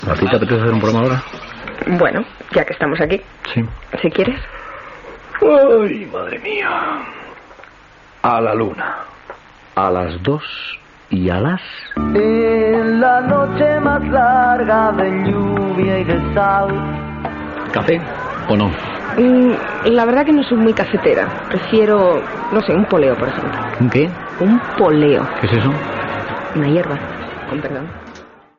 Presentació del programa amb la seva identificació.
Entreteniment
FM